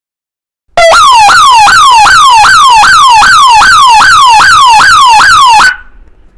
SIRENE ELETROMAGNÉTICA BITONAL 12V ALARMSEG
• Som bitonal: Emite um padrão de som alternado caracterizado pelo conhecido "au au", altamente eficaz para chamar a atenção mesmo em ambientes ruidosos.
SOM(au-au) | SOM(contínuo)
almseb12_uau-uau.mp3